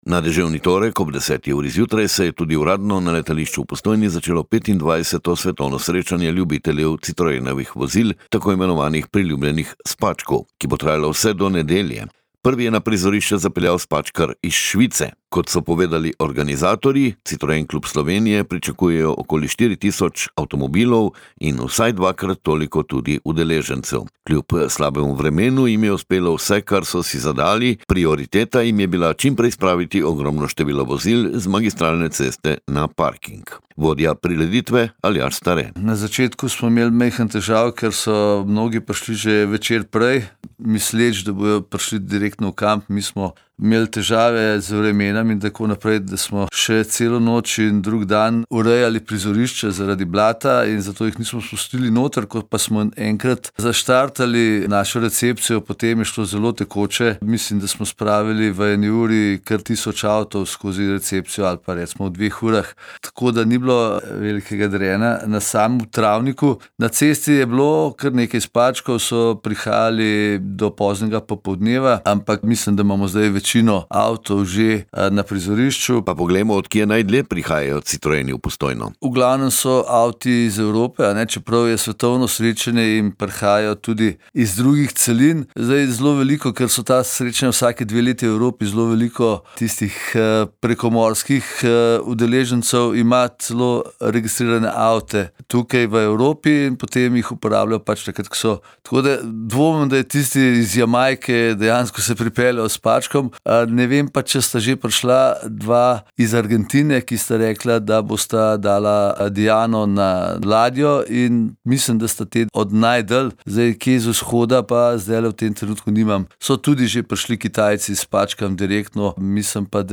p400-55-letalisce-je-polno-spackov-komplet.mp3